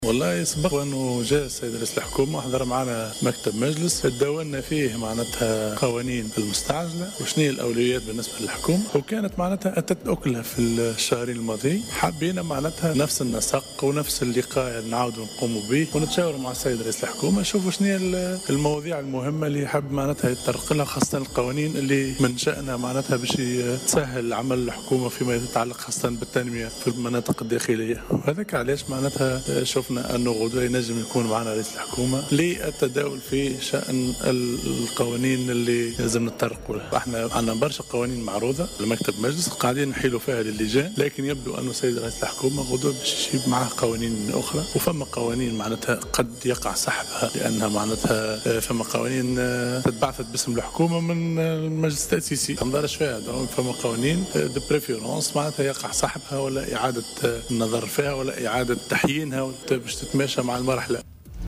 وأكد الناطق باسم مكتب المجلس، محمد بن صوف في تصريح لمراسل "الجوهرة أف أم" ان الاجتماع سيهتم بضبط القوانين المهمة التي من شأنها أن تسهّل عمل الحكومة، خاصة فيما يتعلّق بدفع التنمية في المناطق الداخلية.